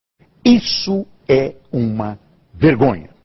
Apresentador Boris Casoy fala seu maior bordão "isso é uma vergonha!"
boris-casoy-isso-e-uma-vergonha.mp3